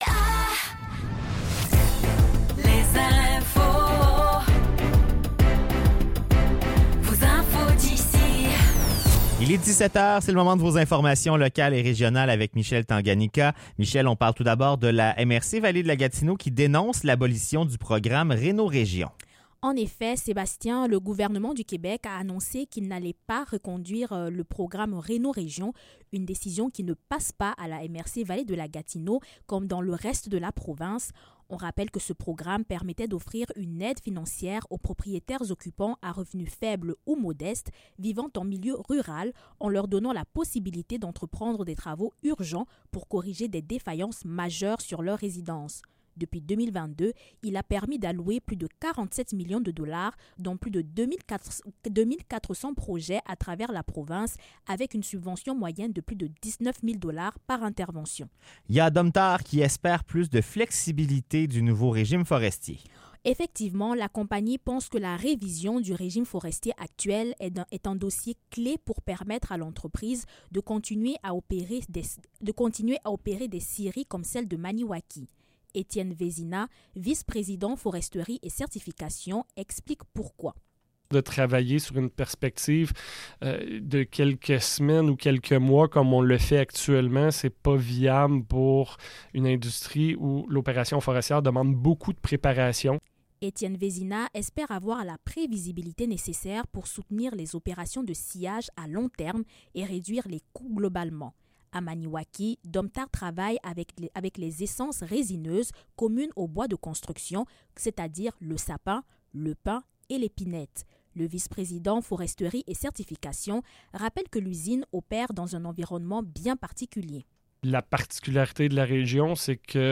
Nouvelles locales - 3 avril 2025 - 17 h
CHGA FM vous informe tout au long de la journéae. Retrouvez les nouvelles locales du jeudi 3 avril 2025 de 17 h.